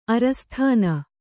♪ arasthāna